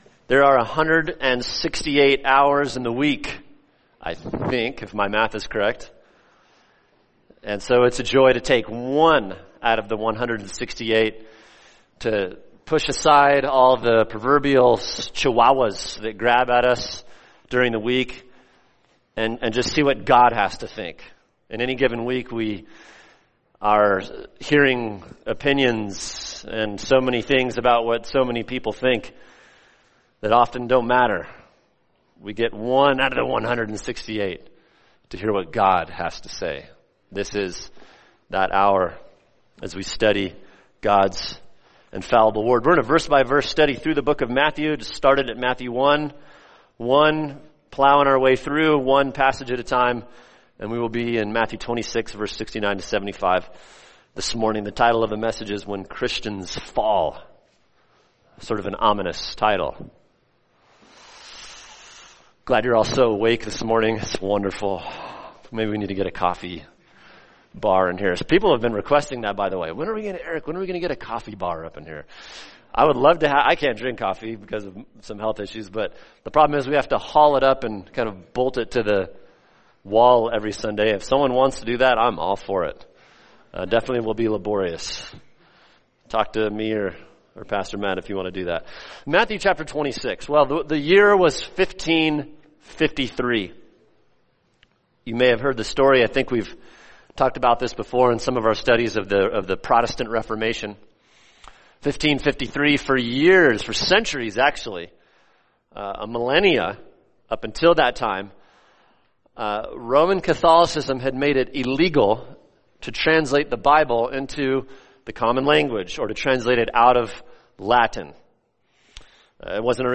[sermon] Matthew 26:69-75 – When Christians Fall | Cornerstone Church - Jackson Hole